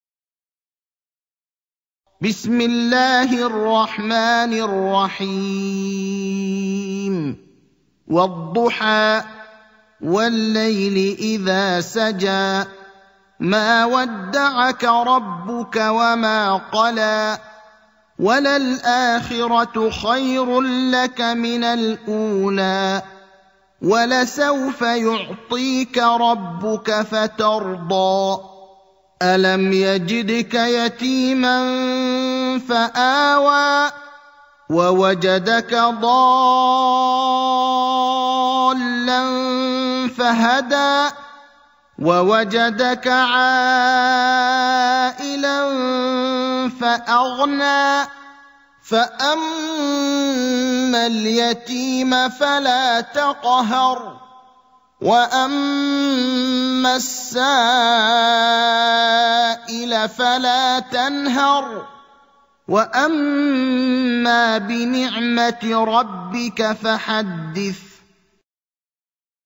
سورة الضحى > مصحف الشيخ إبراهيم الأخضر برواية حفص > المصحف - تلاوات الحرمين